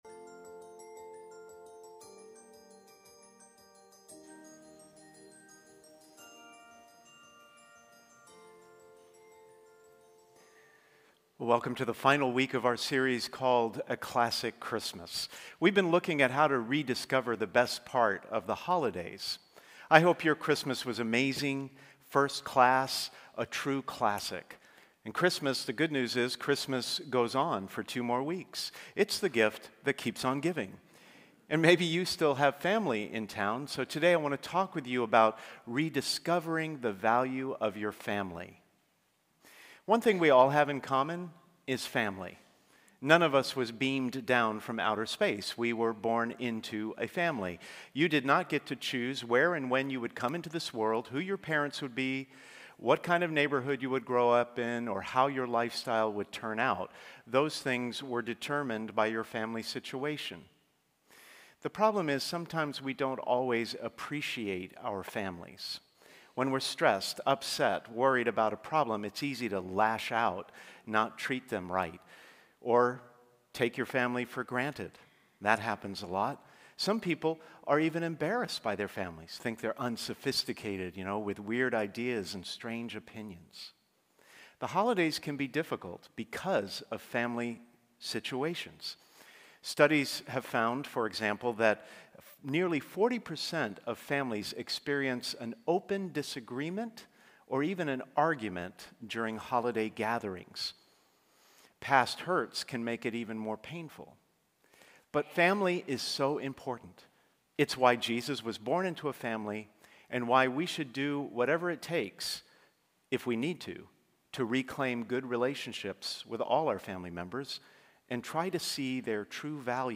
Sermons | St. Hilary Church